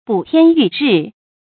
注音：ㄅㄨˇ ㄊㄧㄢ ㄧㄩˋ ㄖㄧˋ
補天浴日的讀法